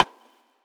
Snares
SGH_RIM.wav